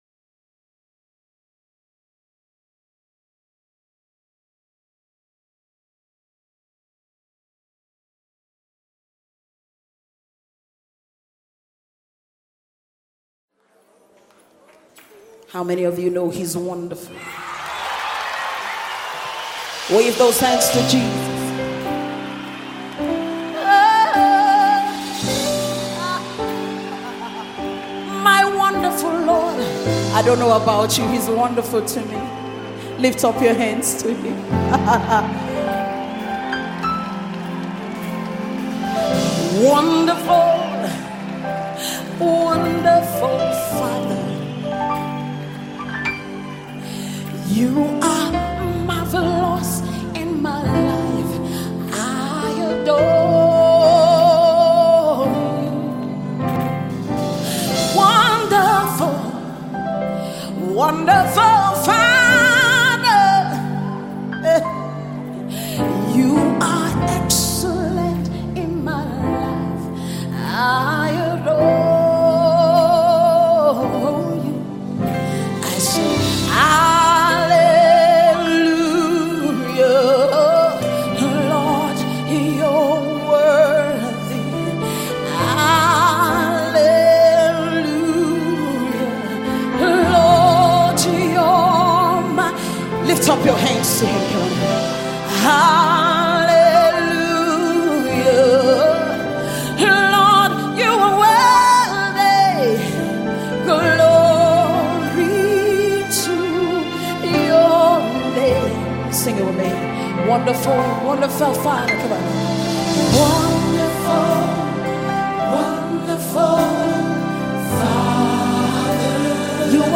September 7, 2024 admin Gospel, Music 0
deep worship single